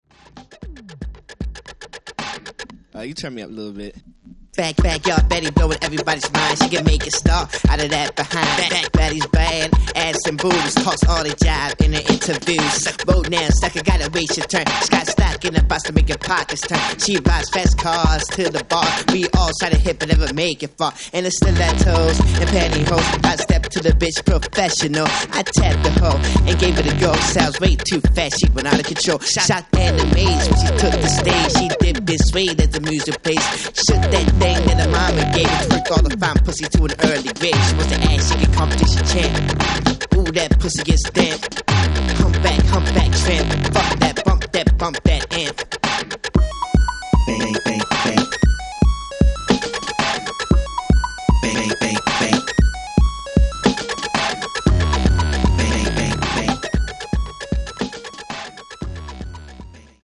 Baltimore club music
Miami bass
Pop, Rock, Psyche, Folk